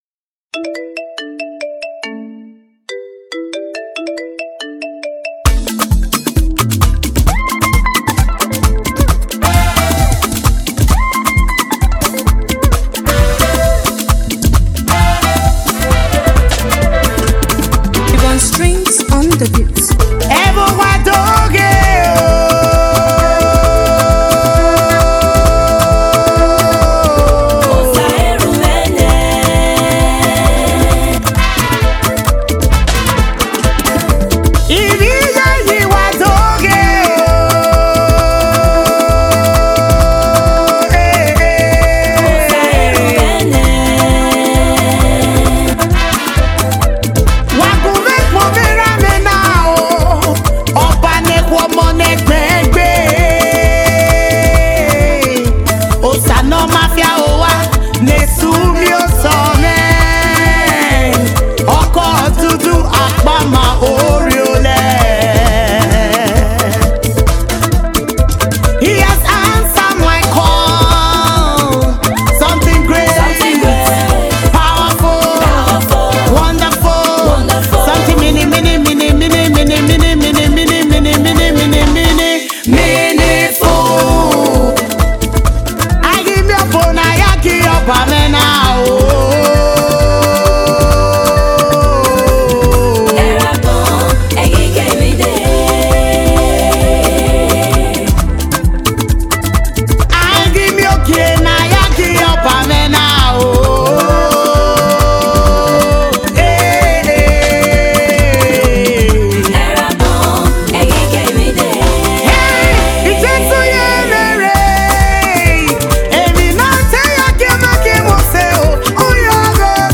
” is a vibrant gospel anthem that celebrates the goodness